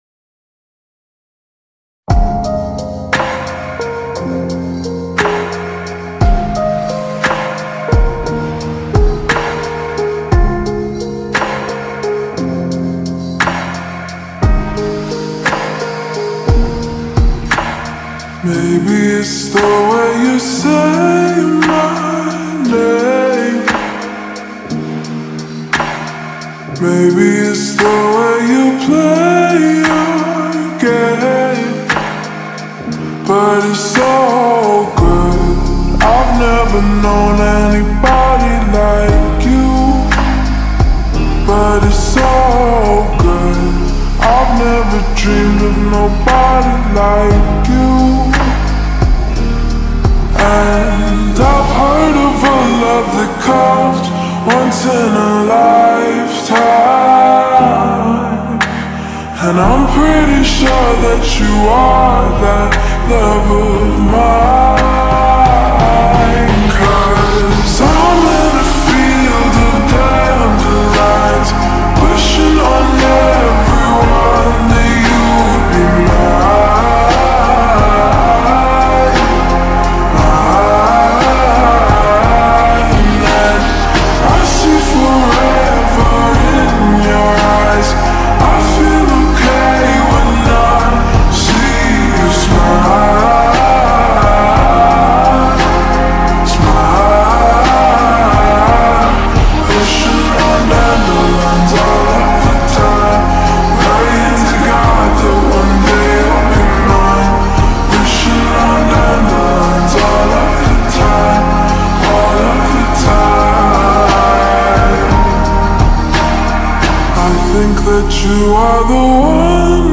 با ریتمی آهسته شده
عاشقانه خارجی